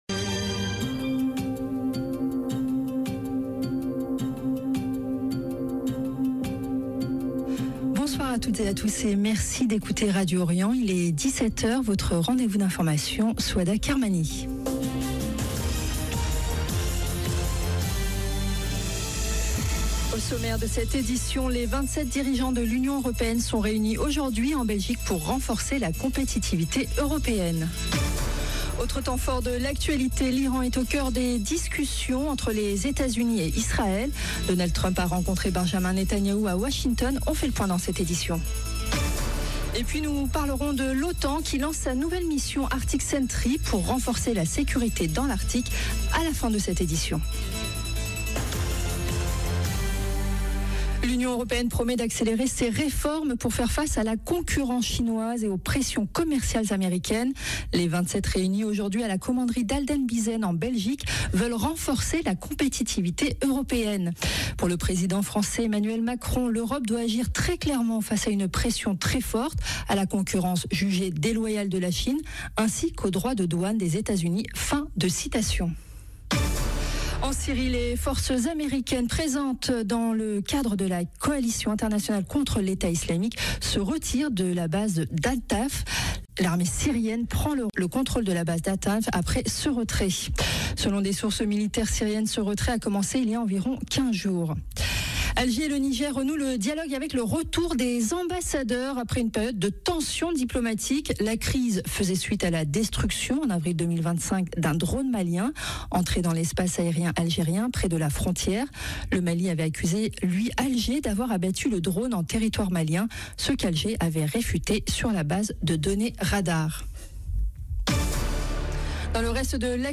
Europe États-Unis Iran Israël Journal 12 février 2026 - 9 min 9 sec Les dirigeants de l'UE reunis pour renforcer la compétitivité, l'OTAN lance une nouvelle mission Radio Orient Journal de 17H Au sommaire de cette édition, les 27 dirigeants de l'Union européenne sont réunis aujourd'hui en Belgique pour renforcer la compétitivité européenne.